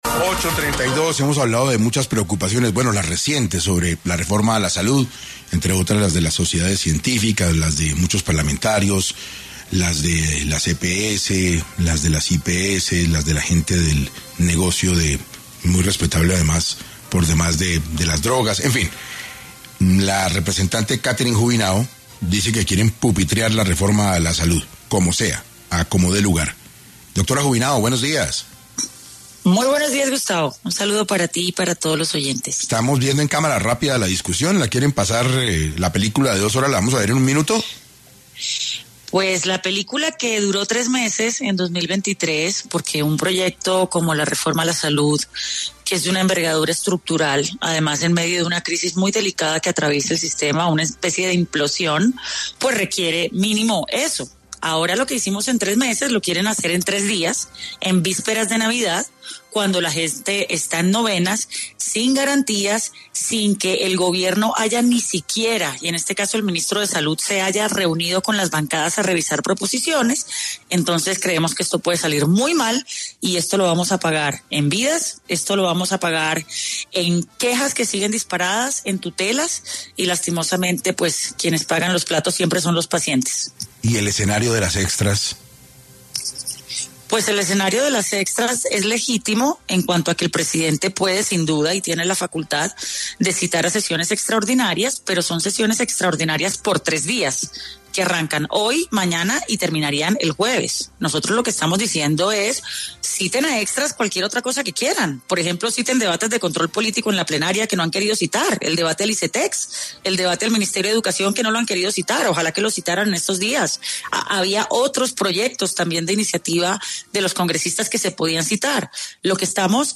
Catherine Juvinao, miembro en la Cámara de Representantes de Colombia, estuvo en ‘6AM’ hablando sobre la reforma a la salud.